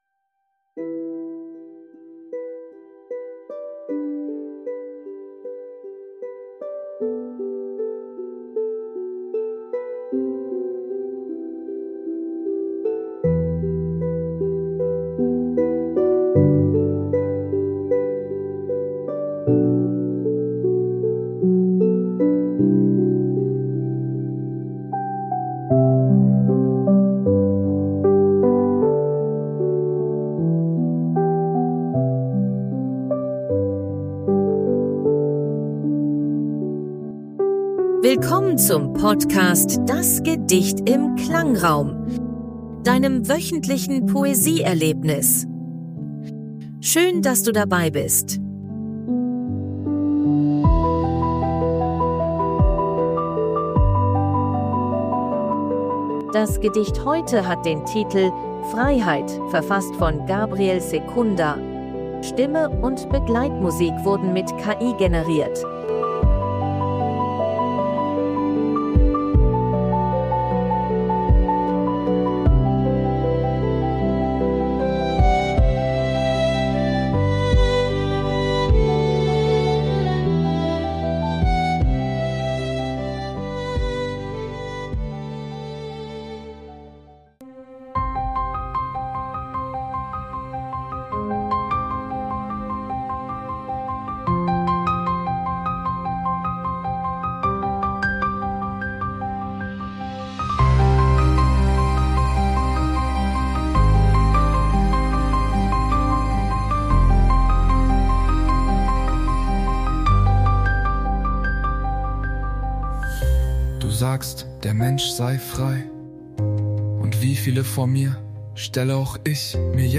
und Hintergrundmusik sind KI-generiert.